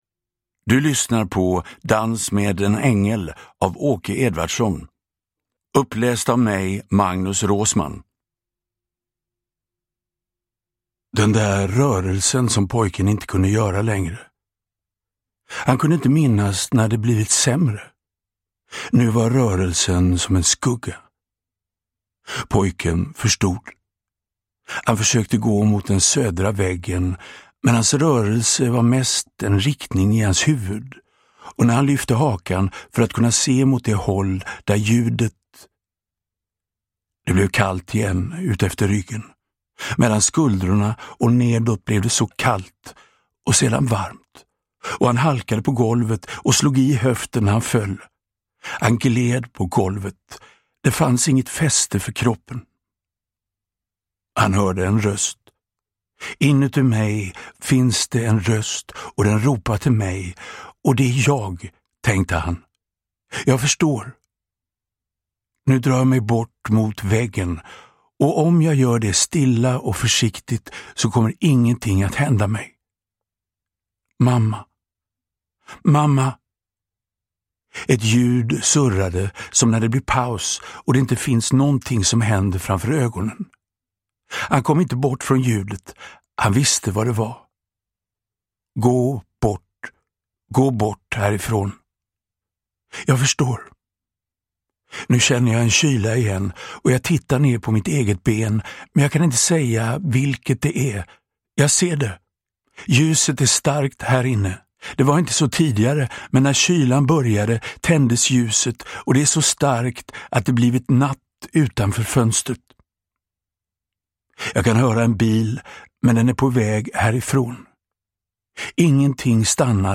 Uppläsare: Magnus Roosmann
Ljudbok